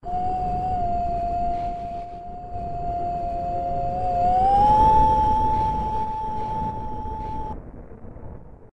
moan2.ogg